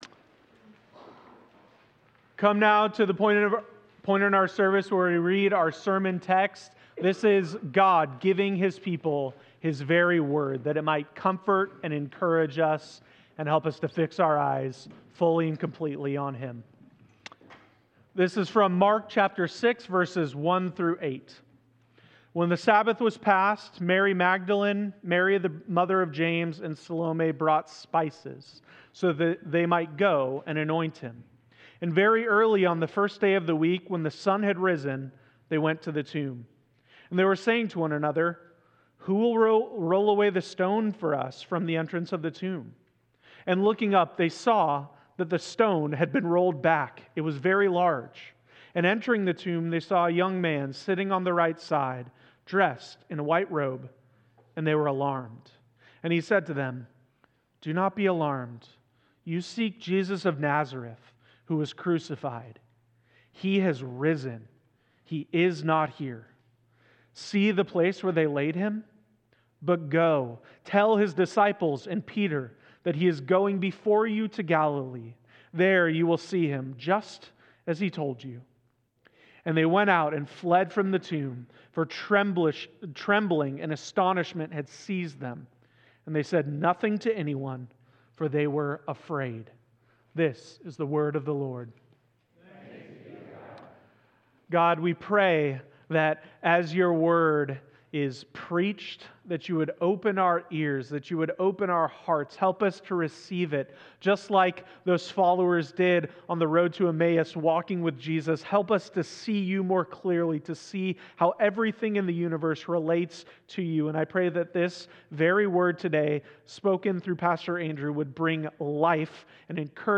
4.20.25 sermon.m4a